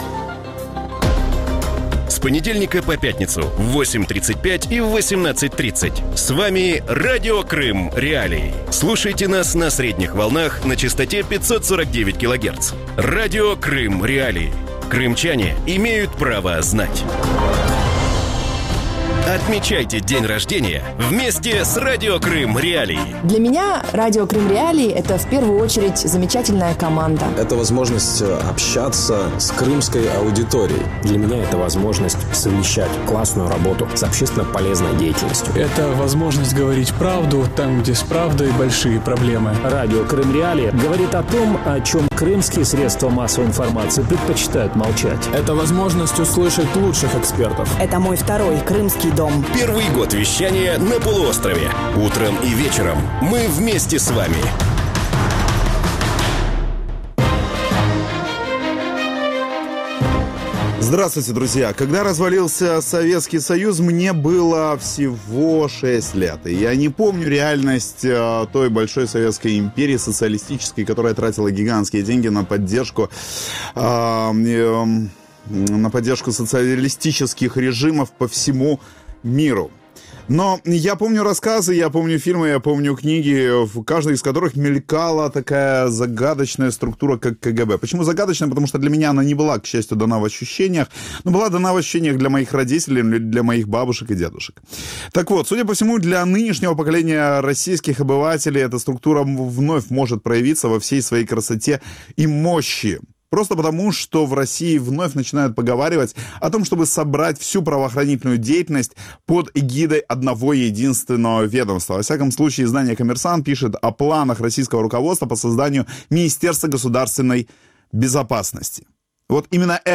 У вечірньому ефірі Радіо Крим.Реалії обговорюють можливе посилення силового блоку після виборів до Державної Думи. Чи з'явиться в Росії суперсиловое відомство, чи будуть обмежувати доступ в інтернет і якою буде Росія перед президентськими виборами?